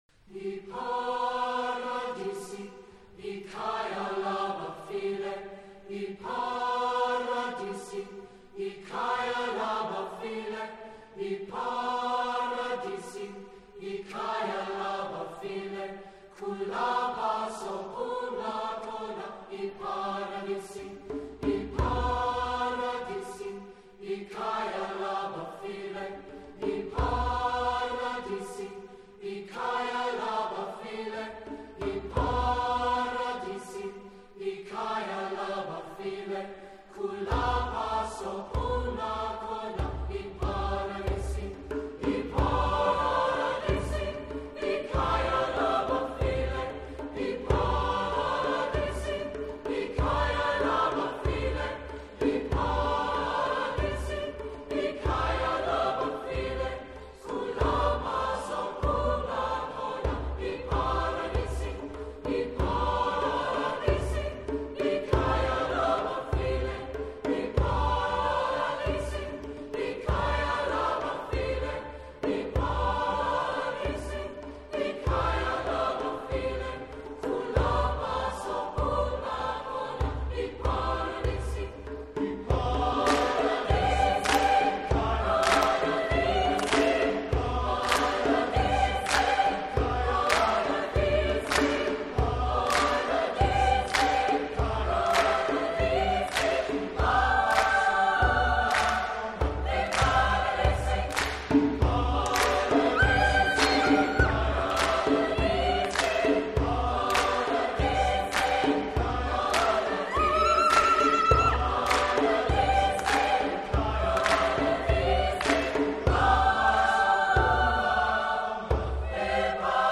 Voicing: SATB/Djemb